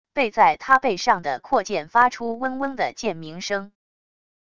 被在他背上的阔剑发出嗡嗡的剑鸣声wav音频